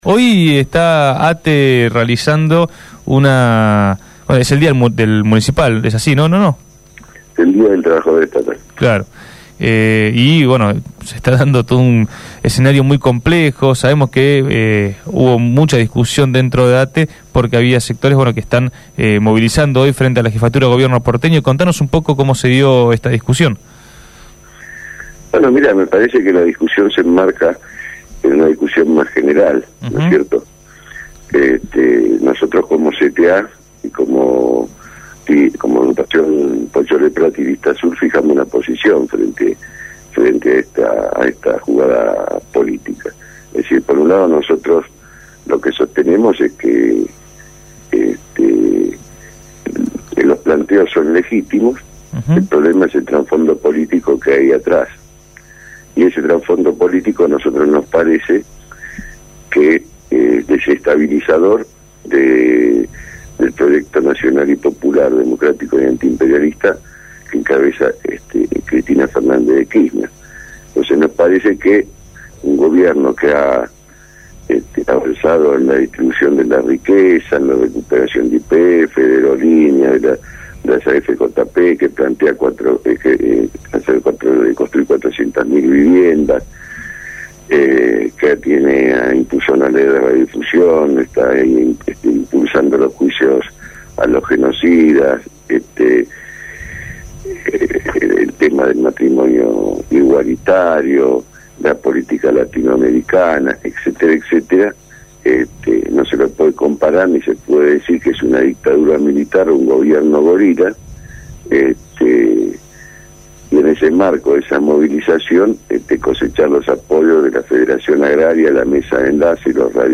Acto de la CGT: entrevista